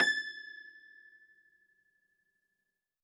53r-pno21-A4.wav